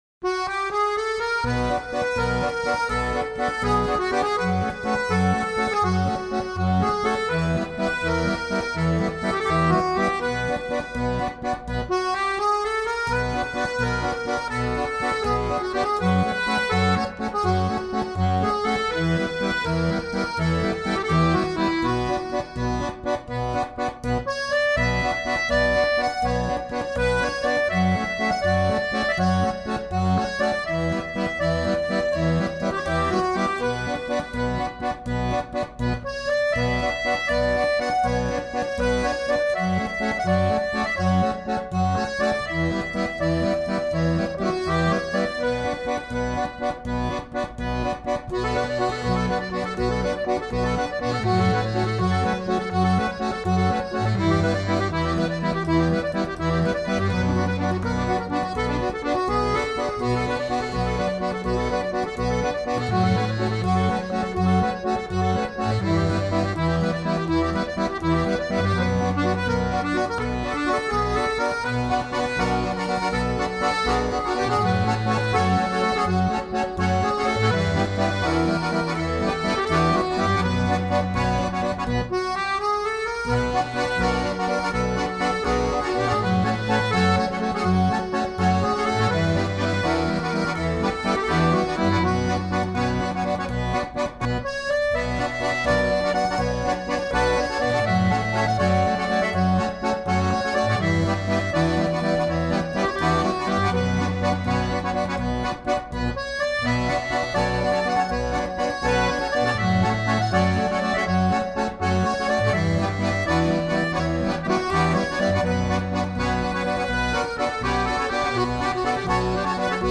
音乐类型:Original Soundtrack
Instrumental
层层叠叠的钢琴、小提琴、手风琴、鼓声、口琴
主旋律带出一次次变奏，旋律就这般悠然荡漾。